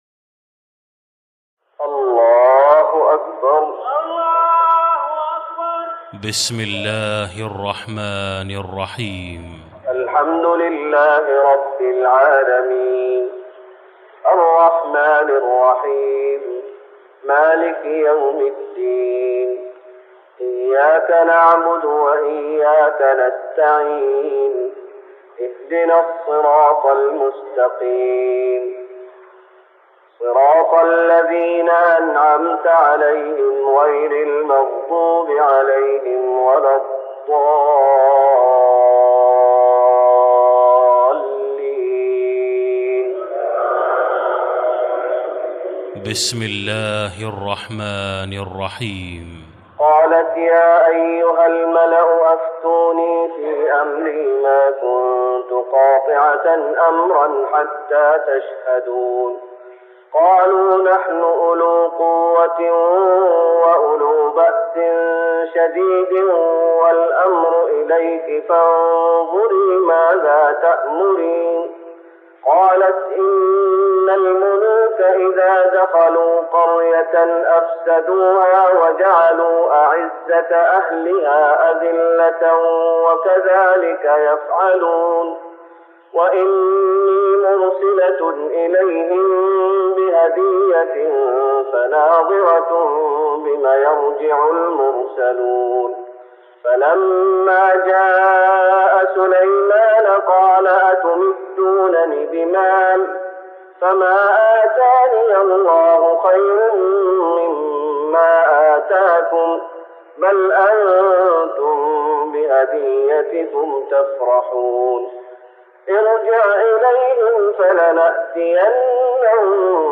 تراويح رمضان 1414هـ من سورة النمل (32-93) Taraweeh Ramadan 1414H from Surah An-Naml > تراويح الشيخ محمد أيوب بالنبوي 1414 🕌 > التراويح - تلاوات الحرمين